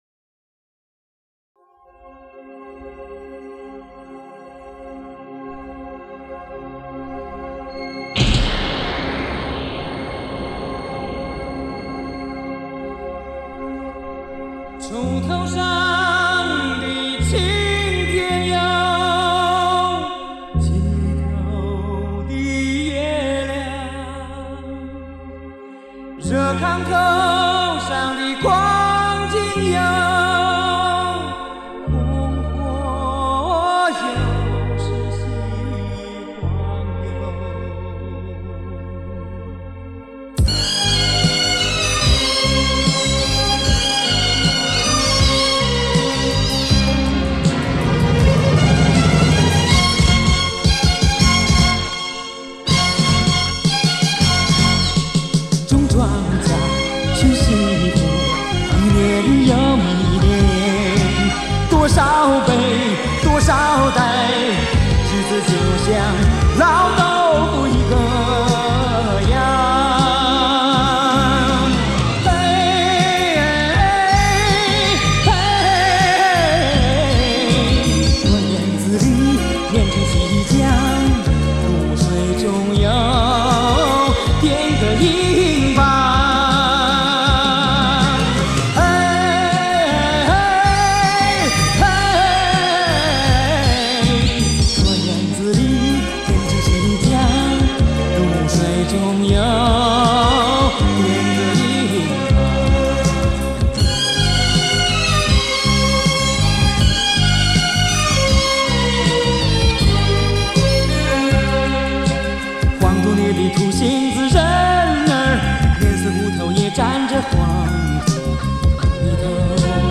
带有乡土气息